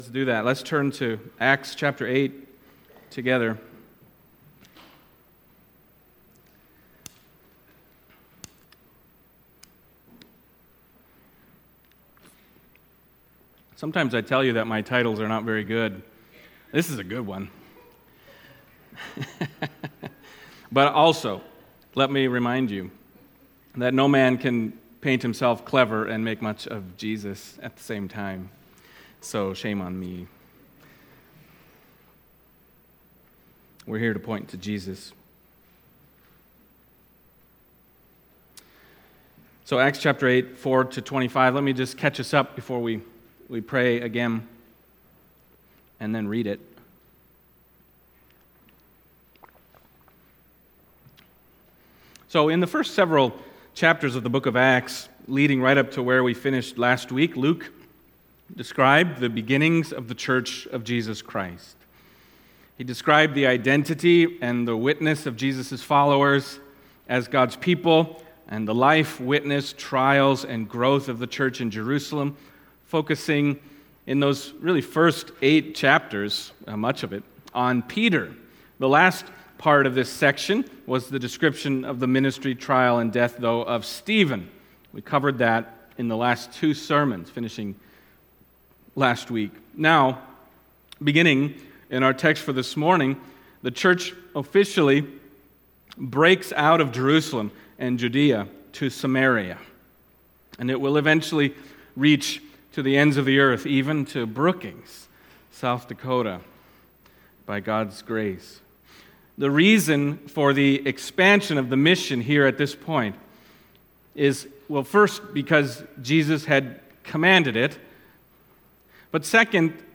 Acts Passage: Acts 8:4-25 Service Type: Sunday Morning Acts 8:4-25 « Stephen